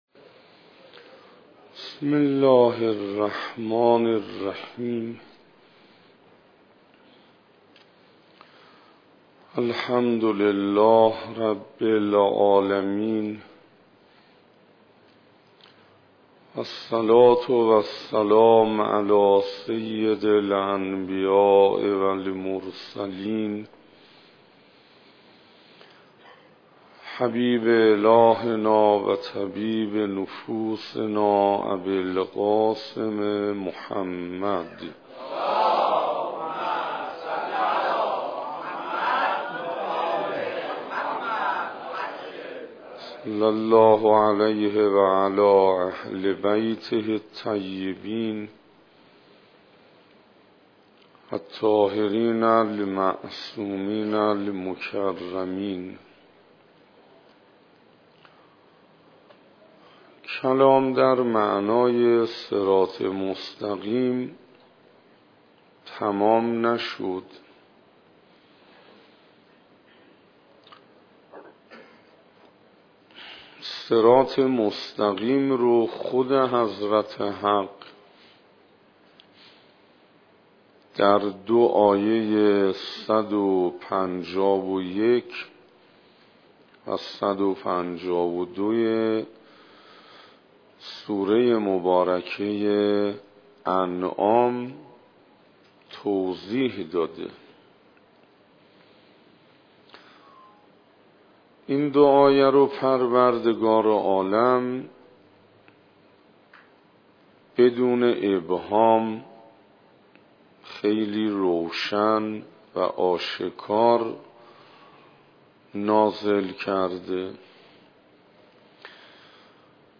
سخنراني بيست و هشتم